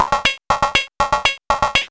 Index of /90_sSampleCDs/Vince_Clarke_Lucky_Bastard/SYNTHS/SCI Pro One